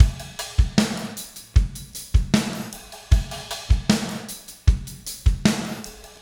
Spaced Out Knoll Drums 02.wav